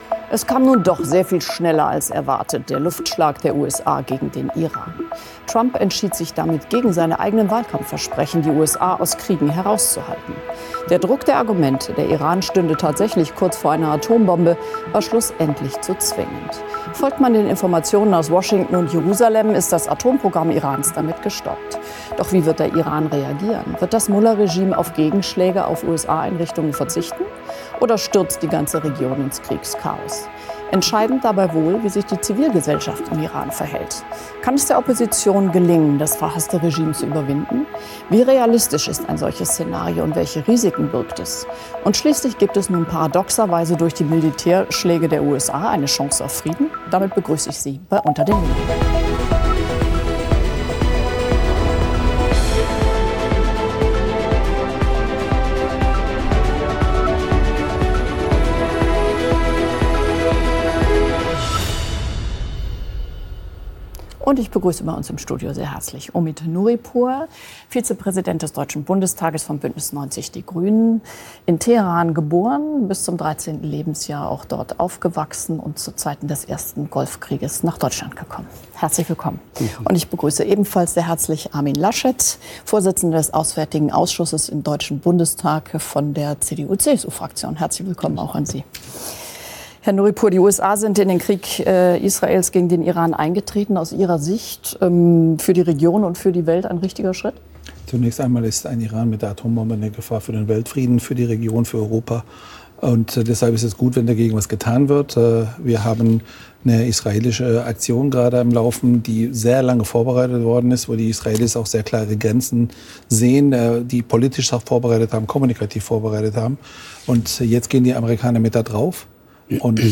„unter den linden“ ist das politische Streitgespräch bei phoenix.
Die Diskussionen sind kontrovers, aber immer sachlich und mit ausreichend Zeit für jedes Argument.